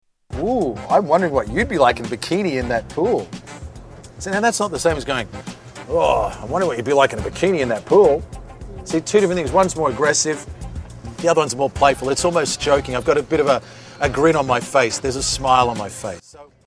Sample of aggressive and playful